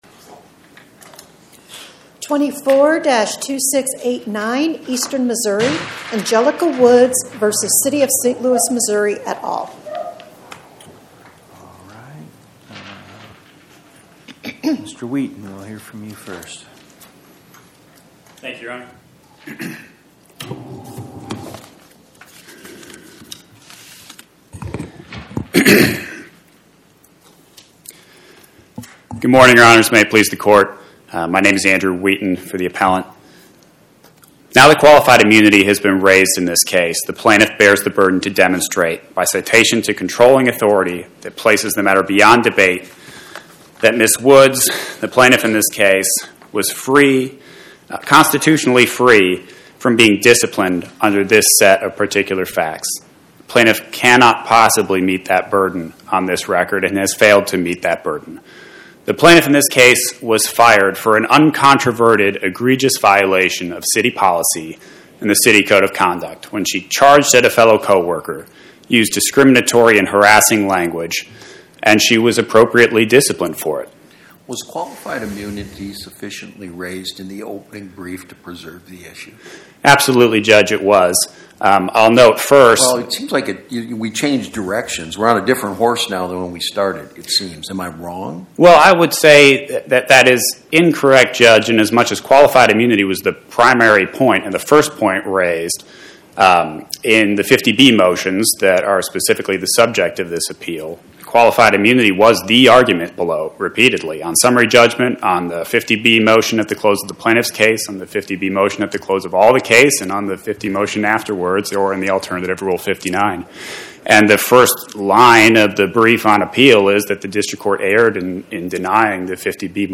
Oral argument argued before the Eighth Circuit U.S. Court of Appeals on or about 11/18/2025